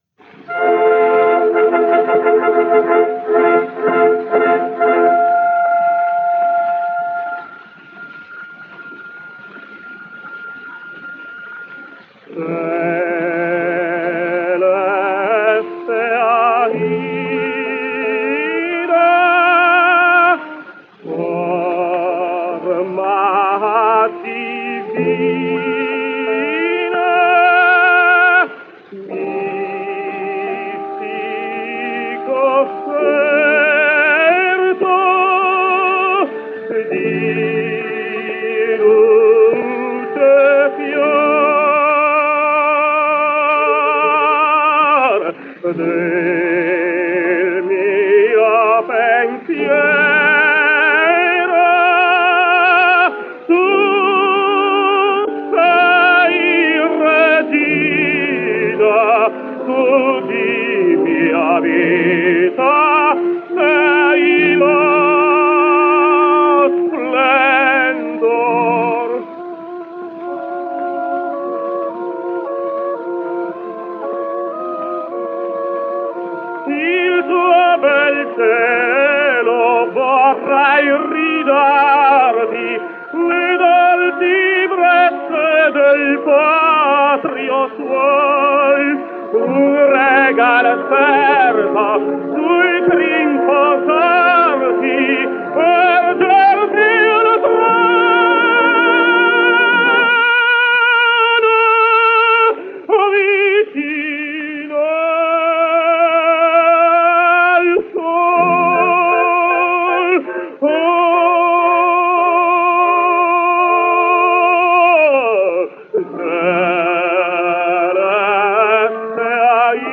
Giovanni Zenatello | Italian Tenor | 1876 - 1949 | Tenor History